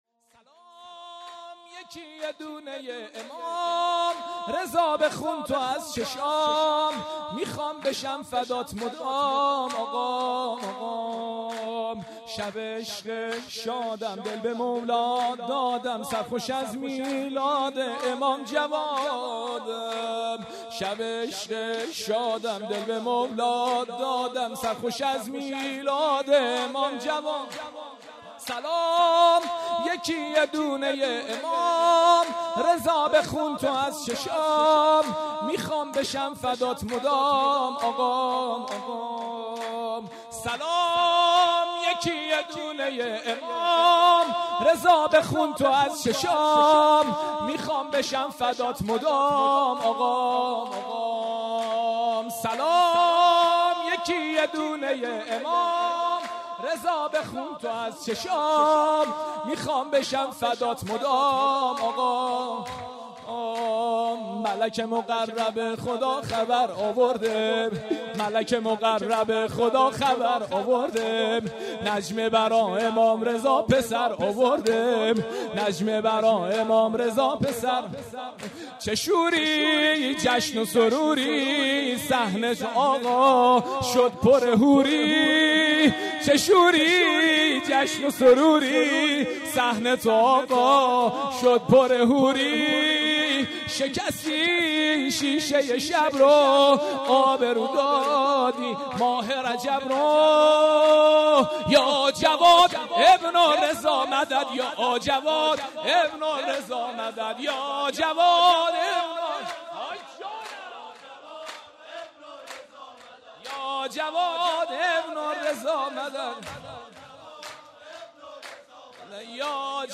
جشن میلاد امام جوادعلیه السلام"1395
سرود «3»